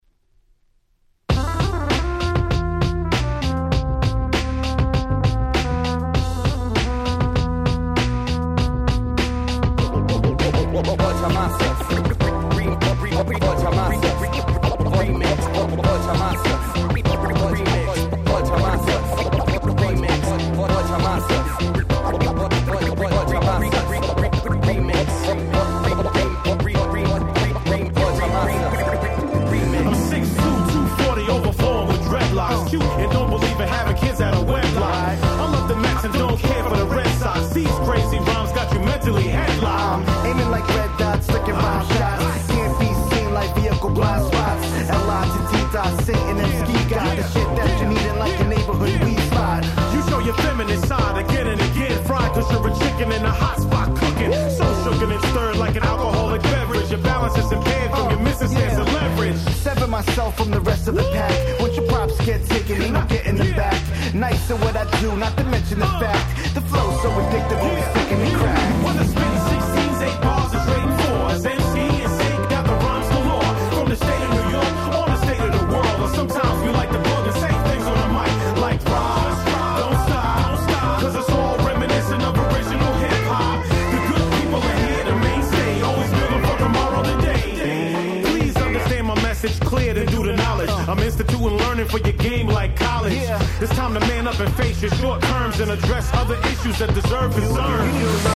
07' Nice Hip Hop !!